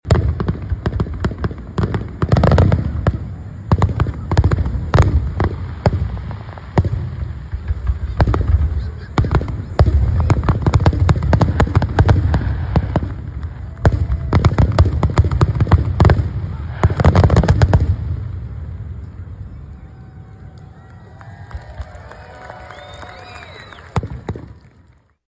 Emporia’s annual fireworks show drew a big crowd to the Emporia State campus, and the crowd may have been a bit bigger than normal because the Emporia Municipal Band brought its weekly concert from Fremont Park to Welch Stadium.
2268-fireworks-2.mp3